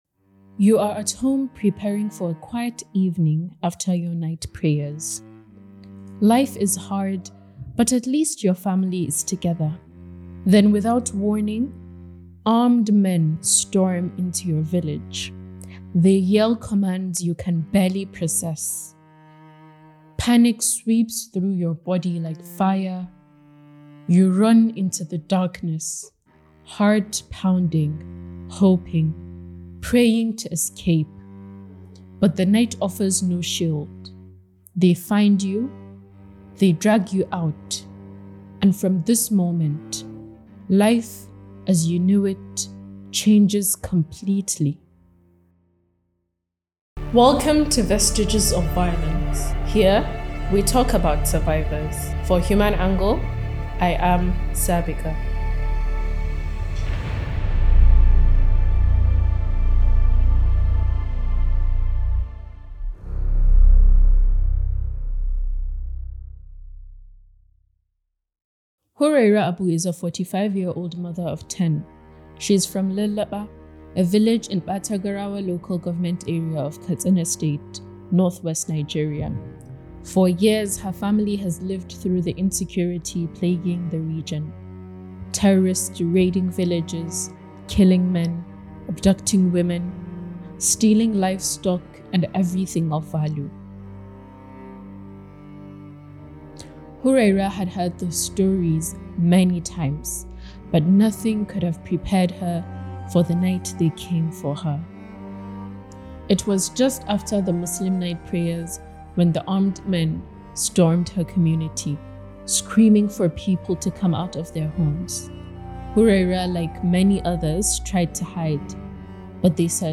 Voice acting